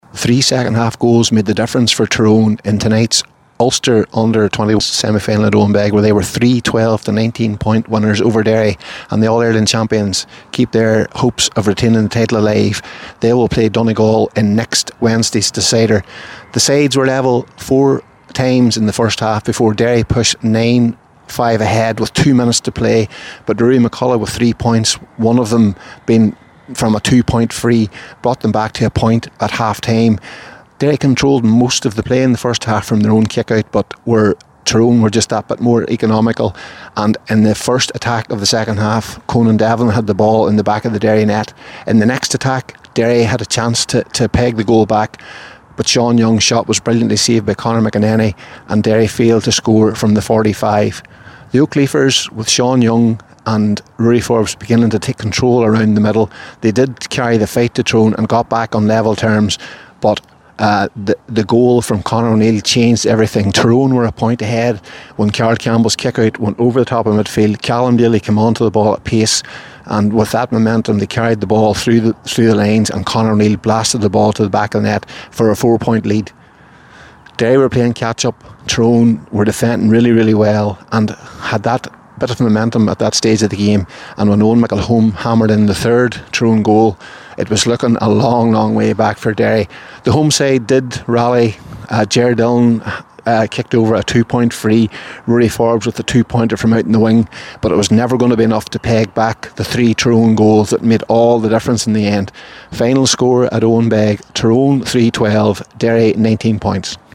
in Owenbeg